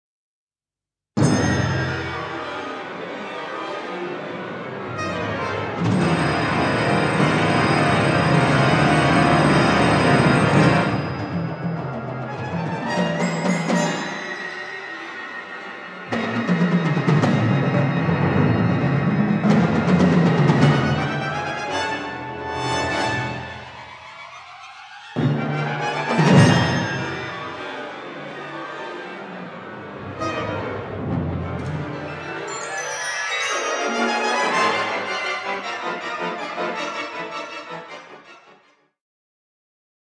Concerto for Organ and Orchestra